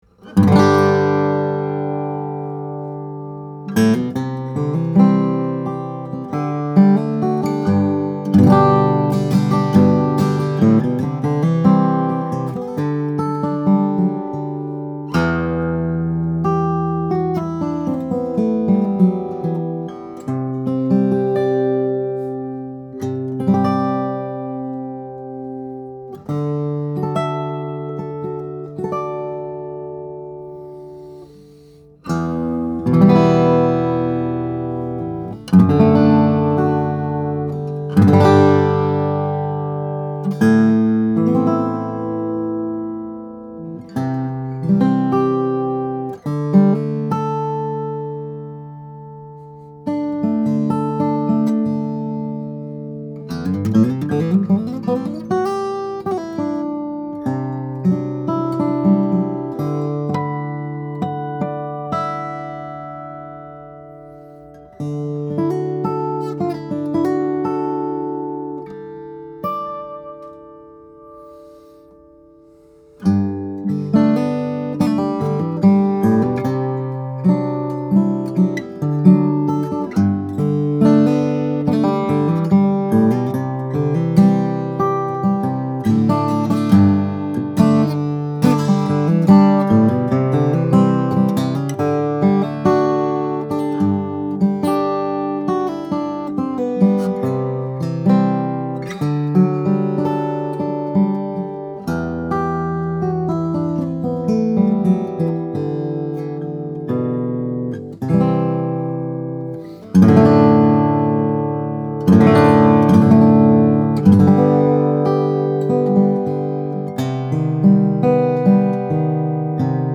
2015 Circa 000 Cutaway, Panamanian Rosewood/Adirondack Spruce - Dream Guitars
Here we have a 12-fret small body guitar with the look and feel of a classic fingerstyle guitar but the makeup of something more.